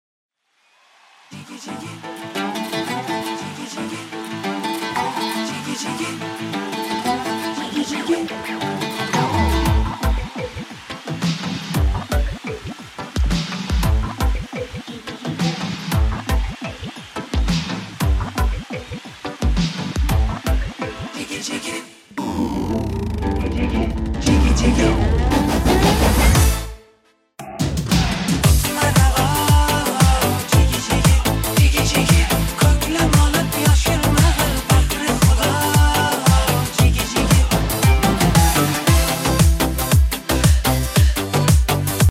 MINUS MUSIC [792]